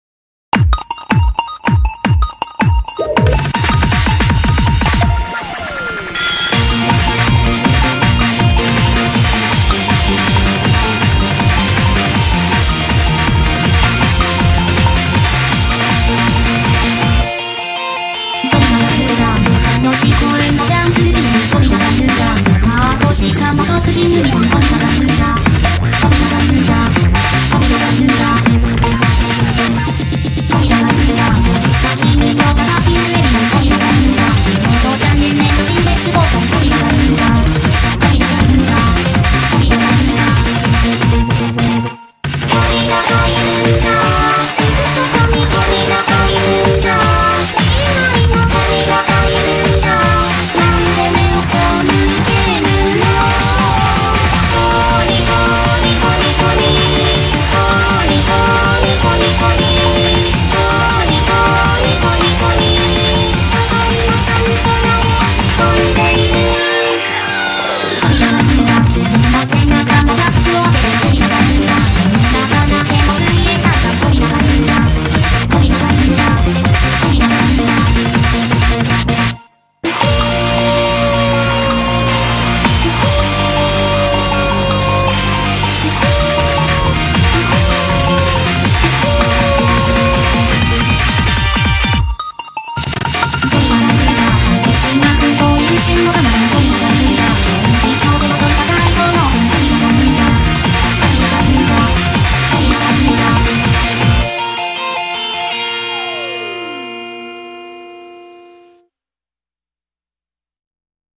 BPM160
Audio QualityPerfect (Low Quality)